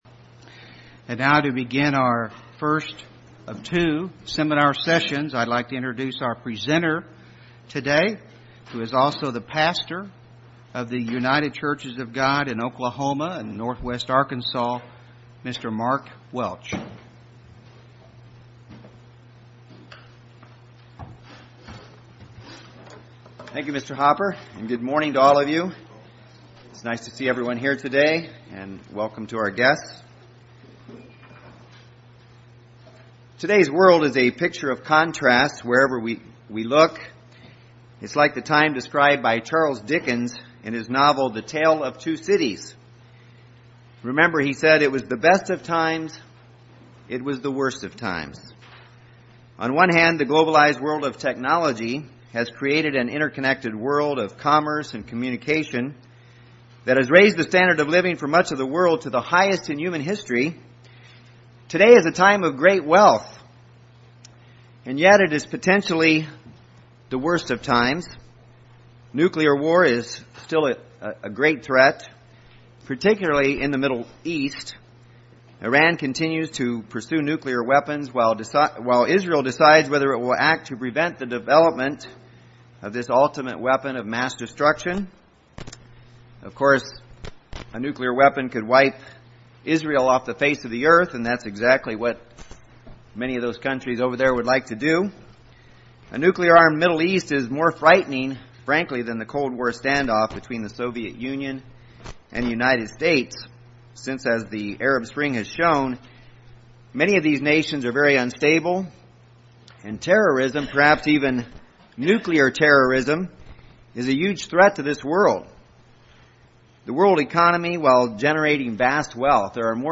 Kingdom of God Bible Seminar Series, Part 3, Session 1 Just what is the Gospel of the Kingdom of God?